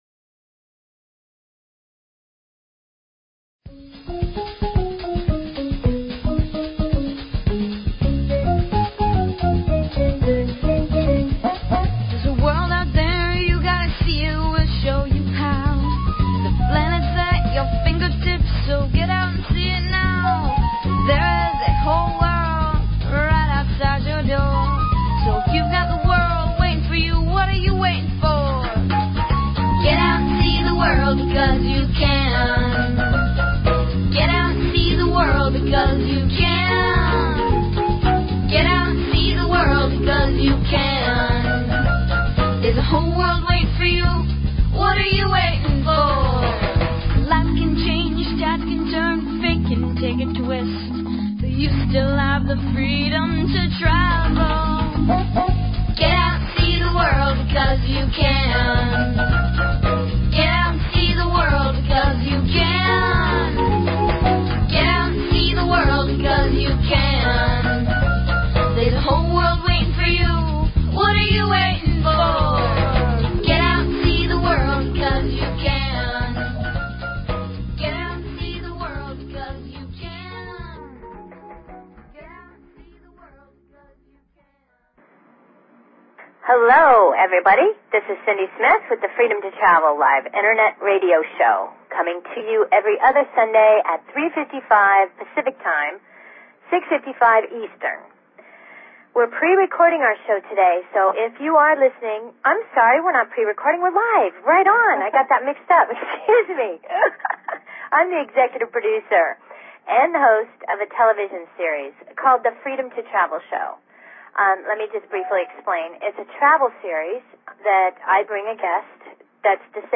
Talk Show Episode, Audio Podcast, Freedom_To_Travel and Courtesy of BBS Radio on , show guests , about , categorized as